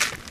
default_gravel_footstep.3.ogg